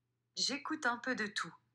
Comparez les réponses vocales de
siri-live.m4a